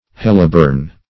Search Result for " helleborein" : The Collaborative International Dictionary of English v.0.48: Helleborein \Hel`le*bo"re*in\, n. (Chem.)